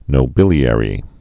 (nō-bĭlē-ĕrē, -bĭlyə-rē)